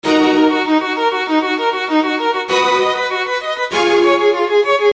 洁净管弦乐2
描述：尖锐的马卡托弦乐和小提琴琶音。
Tag: 98 bpm Orchestral Loops Strings Loops 843.84 KB wav Key : Unknown